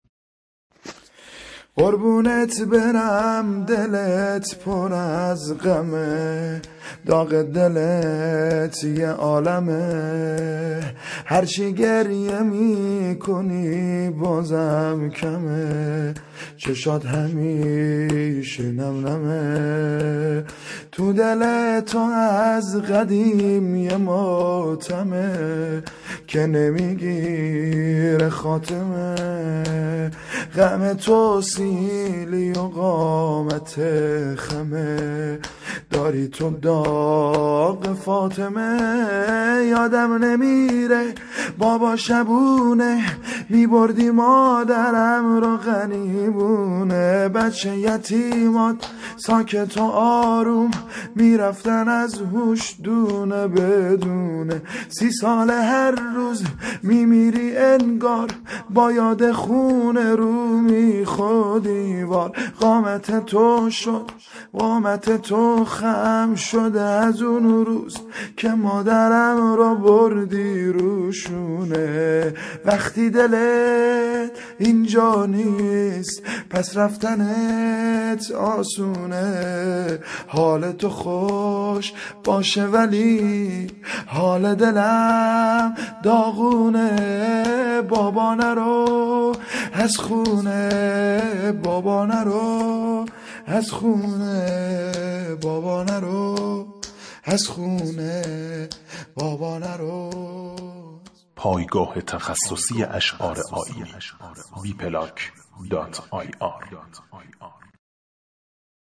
شور - - -